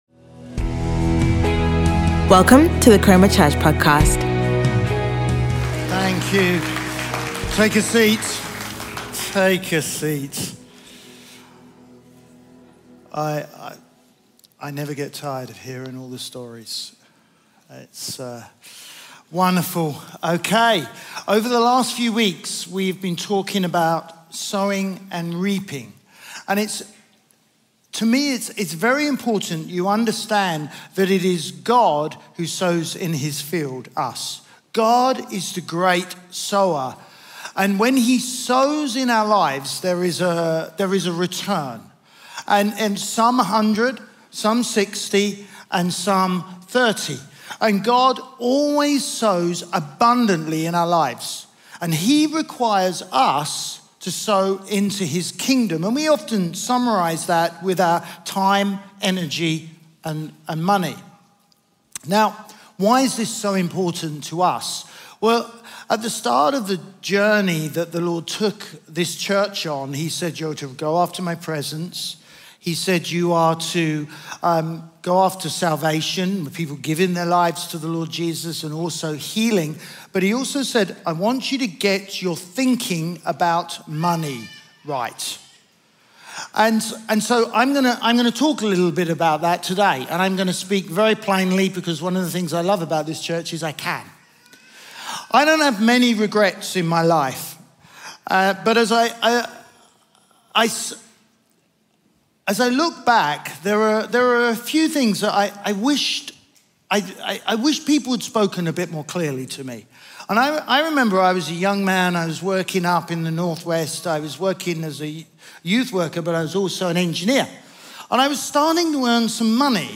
Chroma Church - Sunday Sermon Getting Our Giving Right